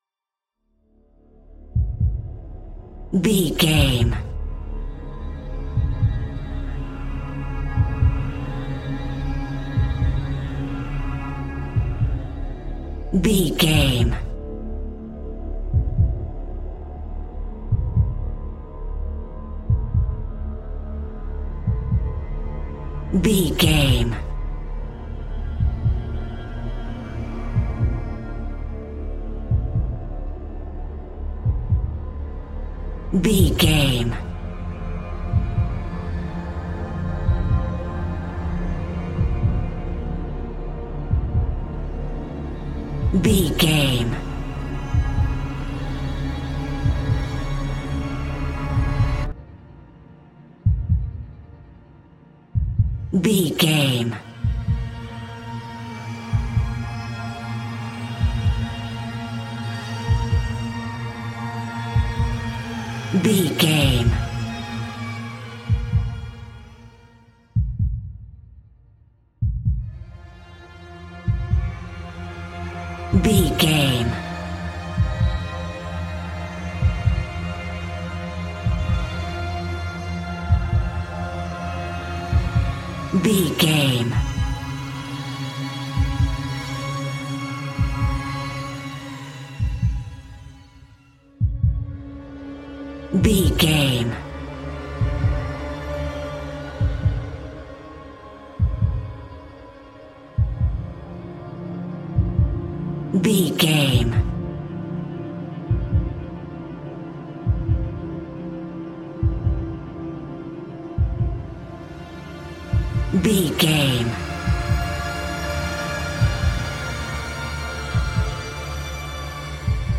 Aeolian/Minor
Slow
tension
ominous
dark
haunting
eerie
strings
synthesizer
ambience
pads